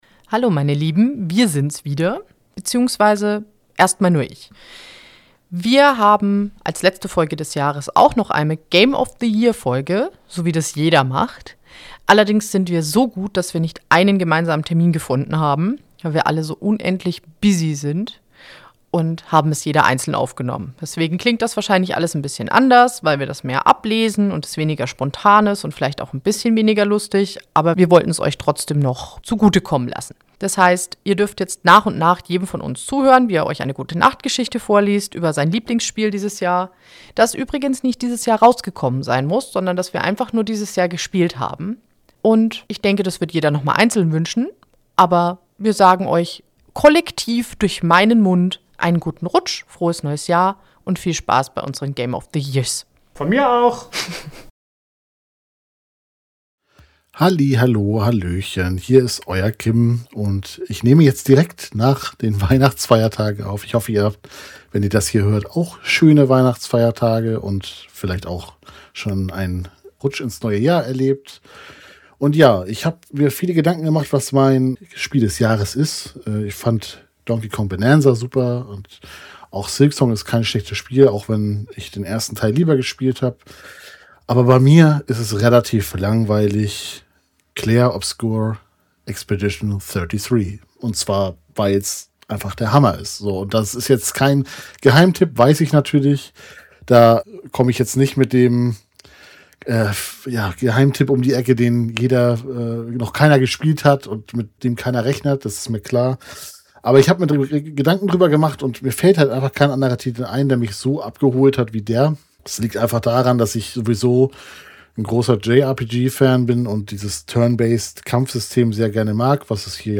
Also hatten wir eine weitere grandiose Idee und haben beschlossen, dass jeder seinen eigenen Teil aufnimmt und das Ergebnis ist unsere Games of the Years-Folge.